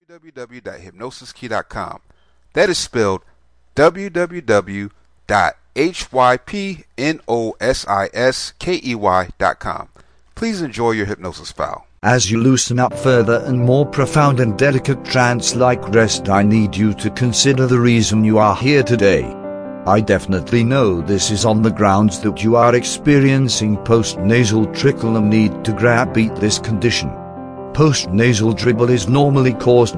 Welcome to Post Natal Drip Self Hypnosis, this is a powerful hypnosis script that helps you get rid of post natal drip.